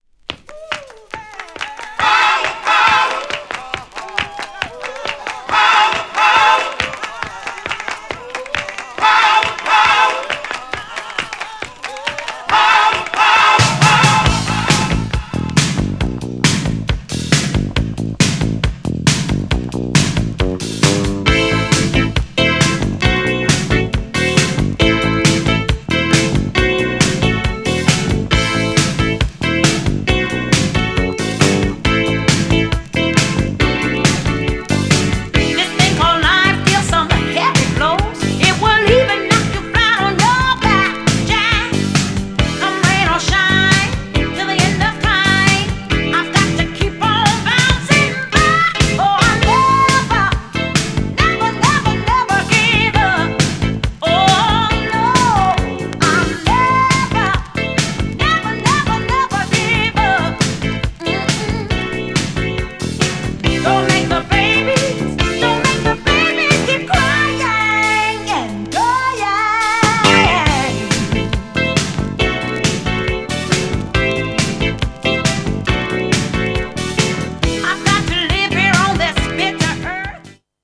GENRE Dance Classic